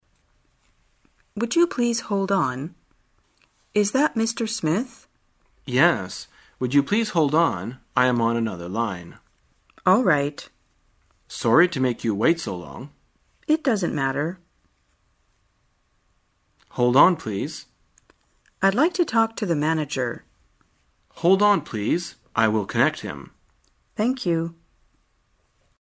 在线英语听力室生活口语天天说 第74期:怎样请对方等候的听力文件下载,《生活口语天天说》栏目将日常生活中最常用到的口语句型进行收集和重点讲解。真人发音配字幕帮助英语爱好者们练习听力并进行口语跟读。